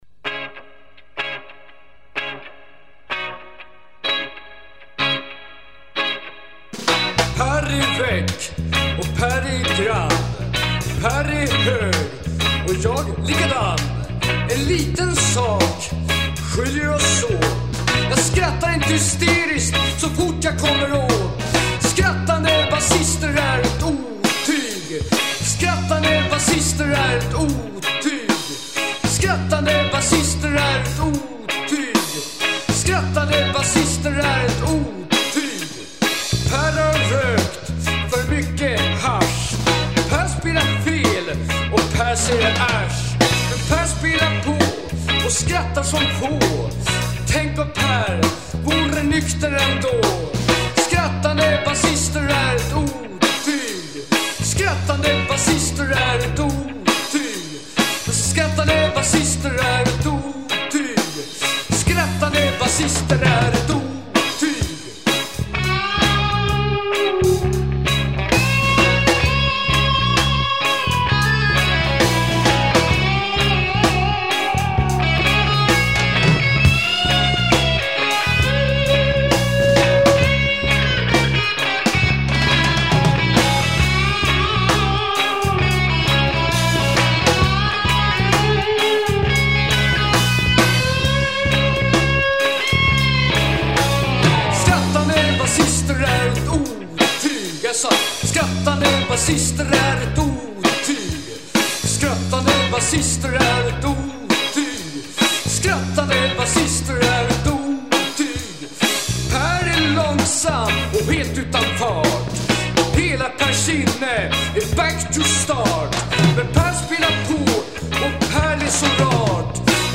Drums
Guitar, Voice
Bass